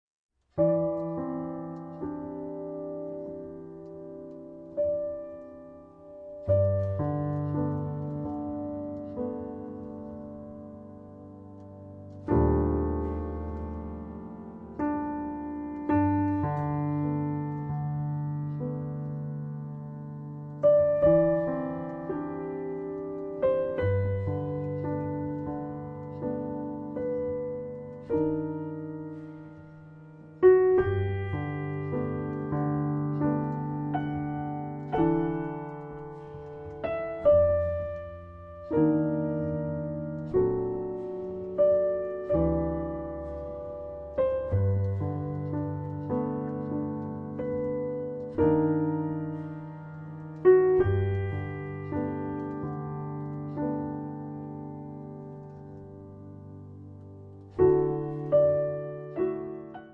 al piano solo, si trasforma in una ballad di alto lirismo.